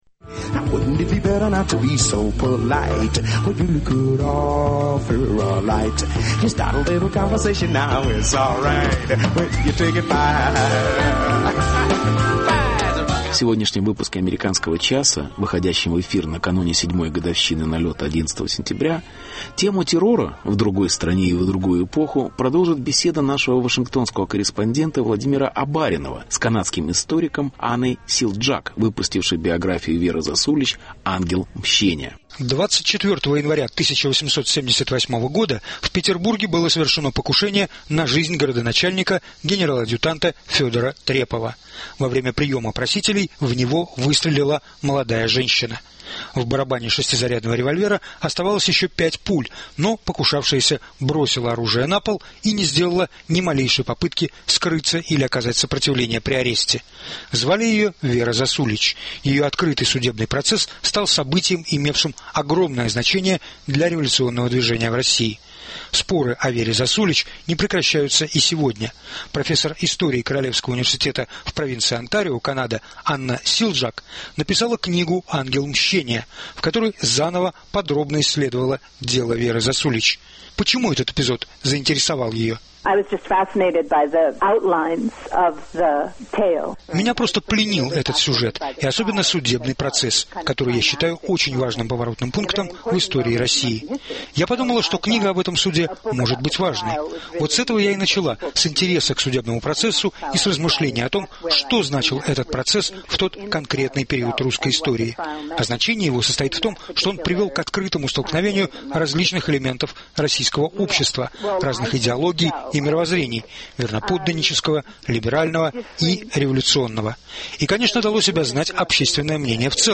Интервью.